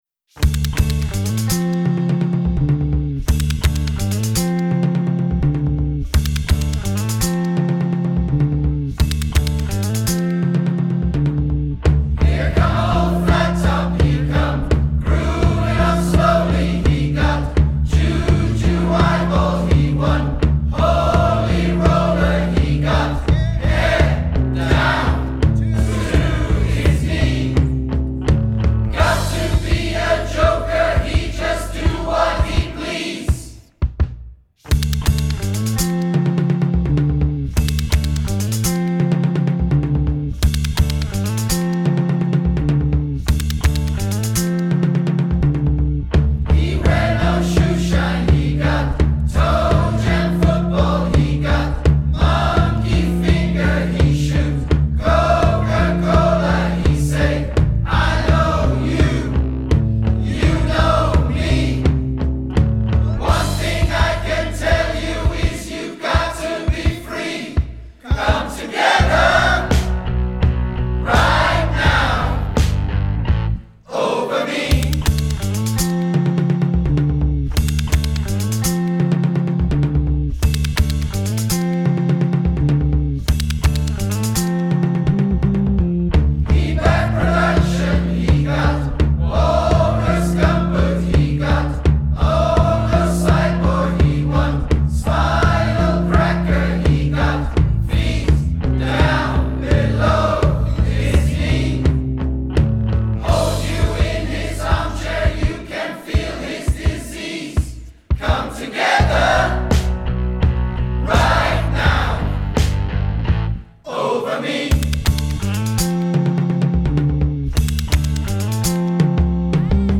Abbey Road Studios